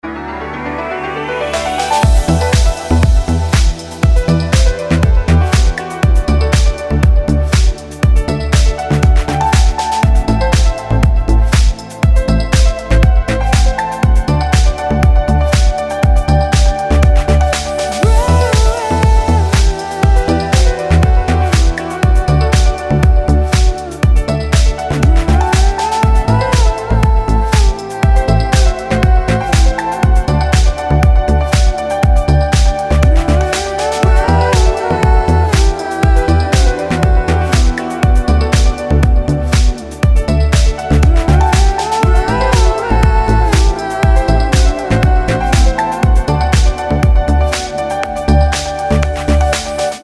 • Качество: 320, Stereo
deep house
мелодичные
dance
красивая мелодия
house